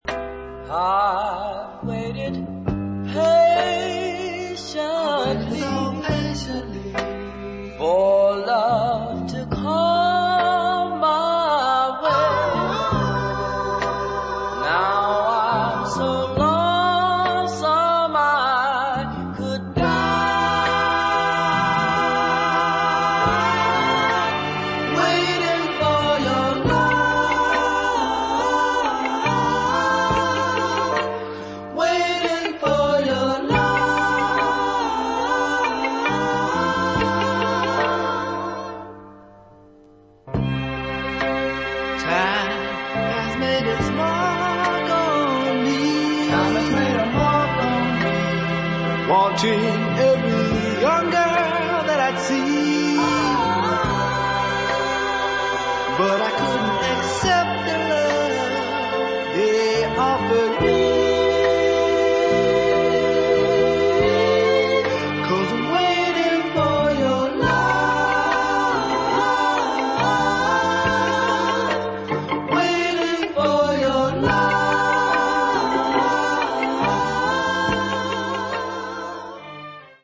Nice Sweet Soul ballad